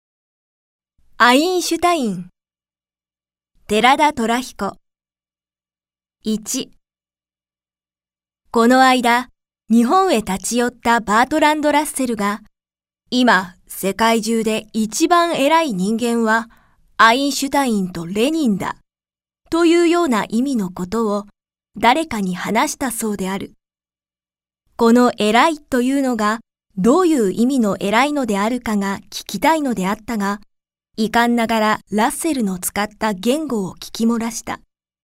朗読ＣＤ　朗読街道119
朗読街道は作品の価値を損なうことなくノーカットで朗読しています。